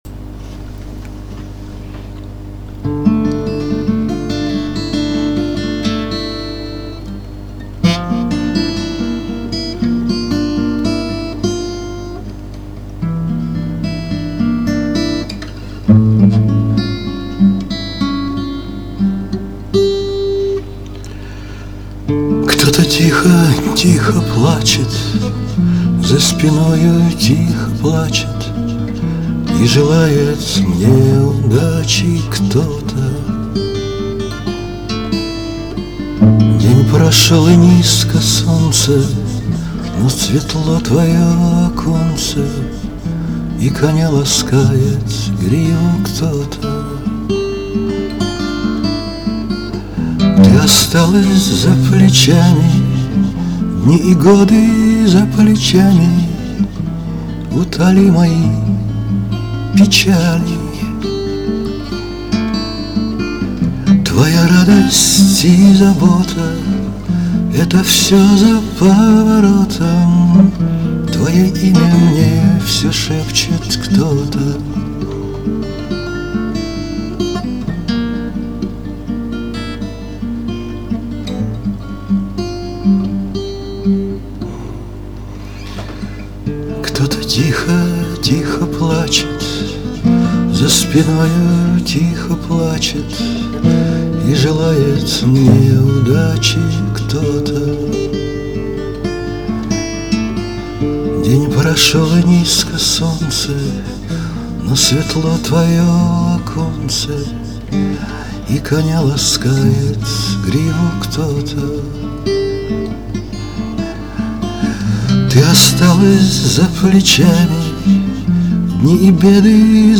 Услышать этот мягкий тембр....